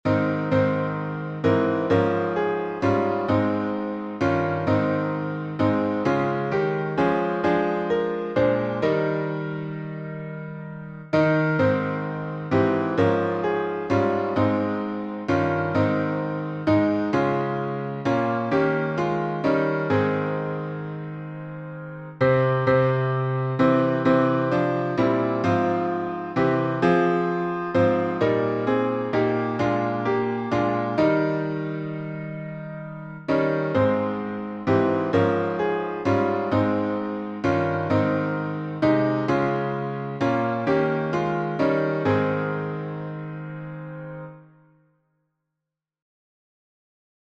It Came Upon the Midnight Clear — A Flat Major.
It_Came_Upon_The_Midnight_Clear_AFlat.mp3